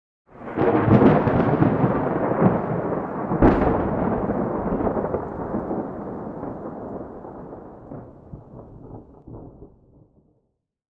storm_5.ogg